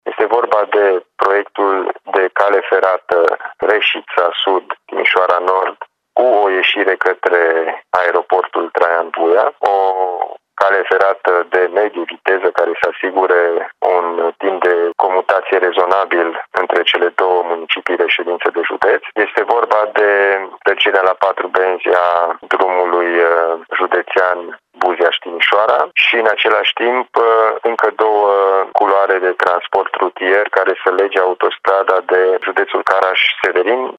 Proiectul de parteneriat pentru mobilitate cu administrația județeană din Caraș-Severin a aprobat de consilierii din Timiș prevede și extinderea la patru benzi a drumului  dintre Timișoara și Buziaș spune președintele Consiliului Județean Timiș, Alin Nica